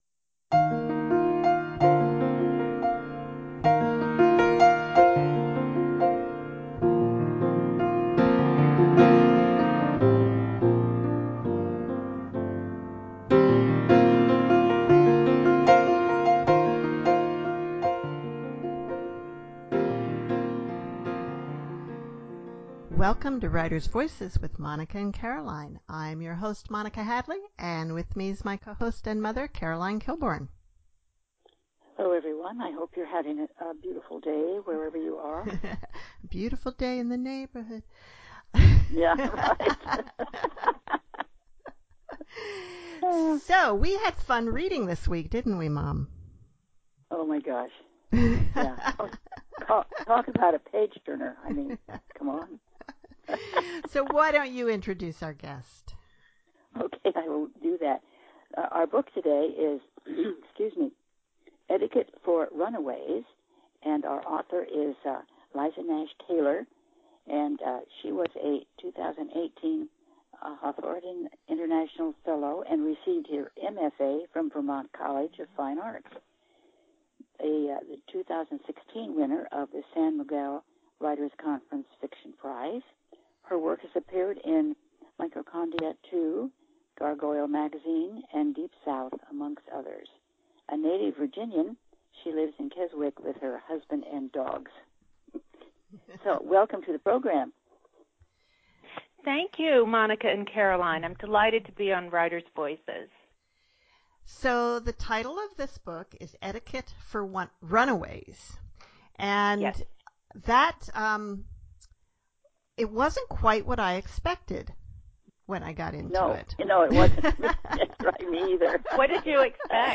This interview is chock full of nuggets for new and aspiring novelists, especially for those writing historical fiction.